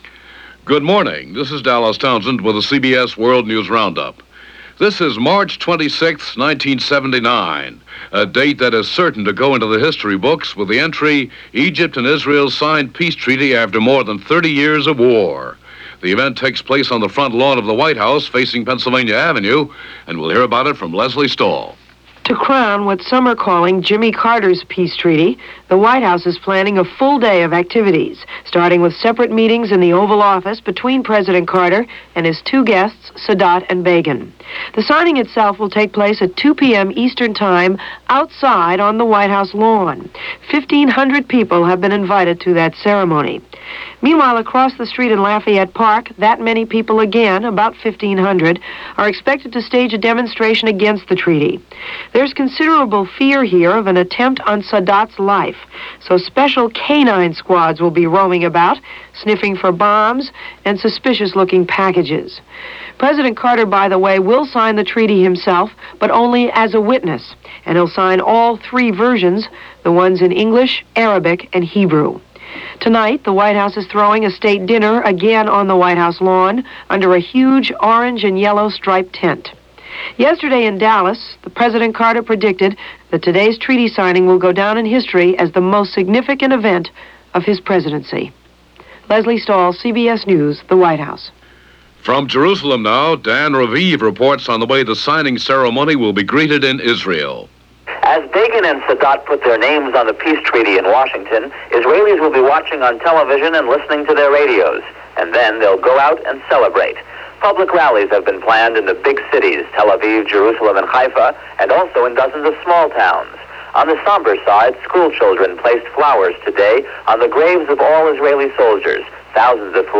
And along with the promise of Peace in the Middle East, that’s just a little of what went on, this historic March 26, 1979 as presented by The CBS World News Roundup.